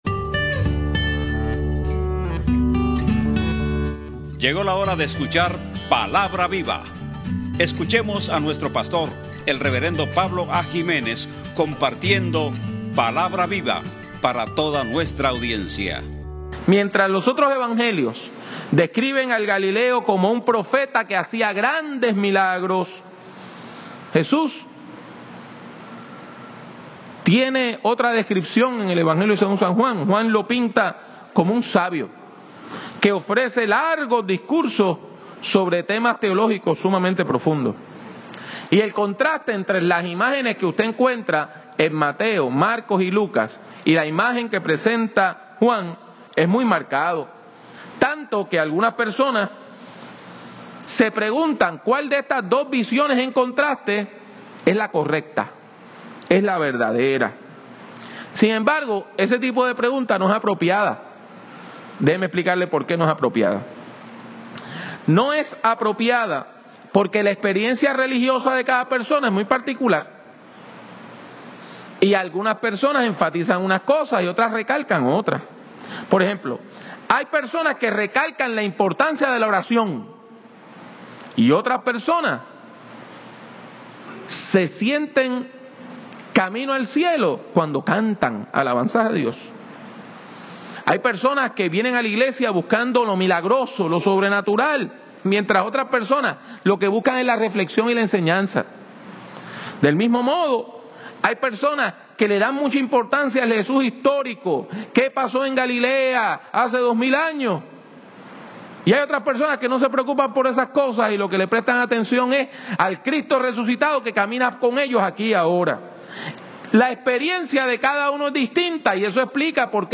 Para que el mundo crea: Un sermón sobre Juan 17:20-27.